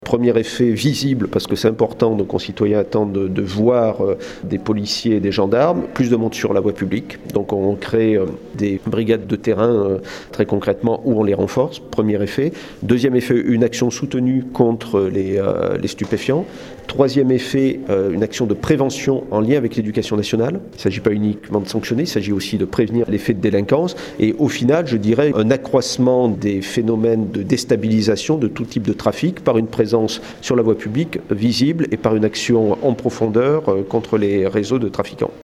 Alain Espinasse, Préfet de la Haute Savoie nous rappelle les effets de ce dispositif.